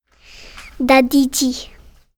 prononciation
daditi-pron.mp3